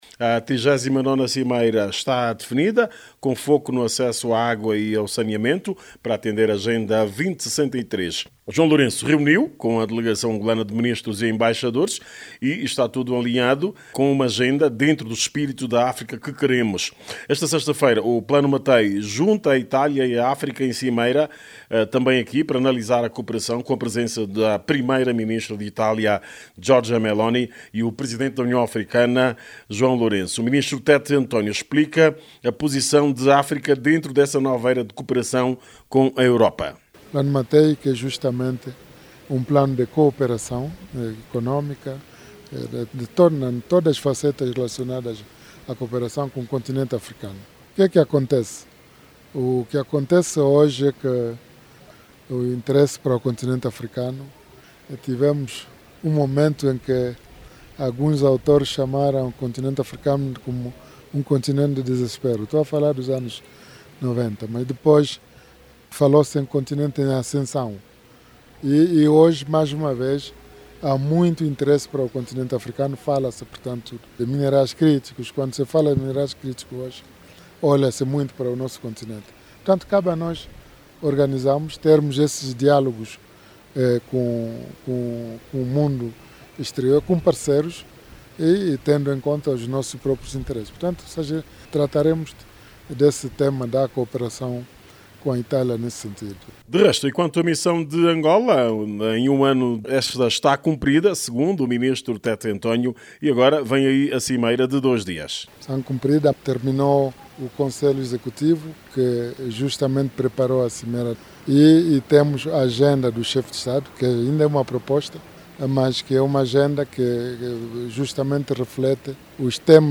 a partir de Adis Abeba: